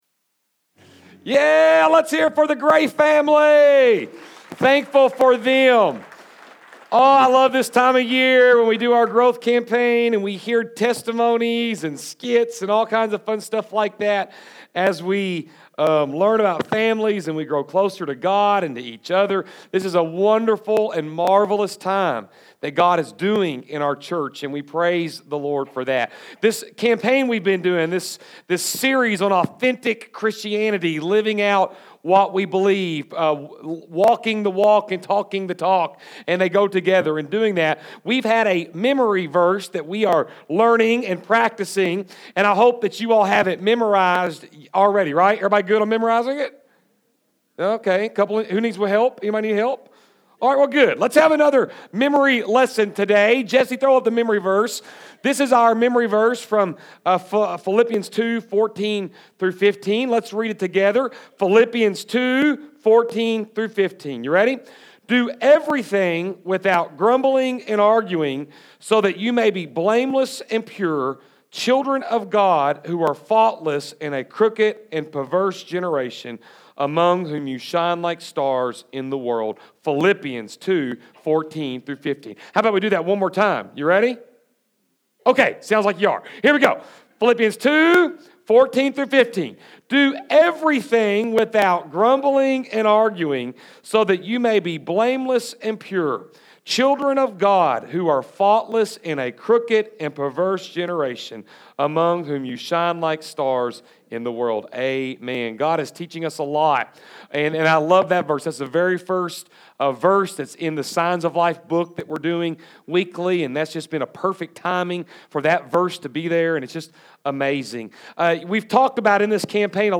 September 19, 2016 Are you Alive?- Open Hands Service Type: Sunday AM | Continuing message in the series “Are you Alive” focusing on Christian generosity.